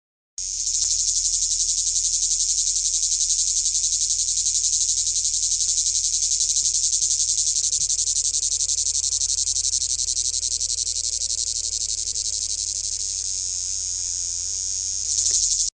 Enregistrement des cymbalisations du mâle Lyristes plebejus.